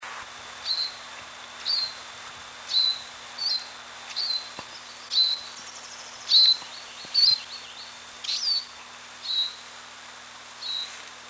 Малая мухоловка
Песня. окр. п. Барда. 27.06.93.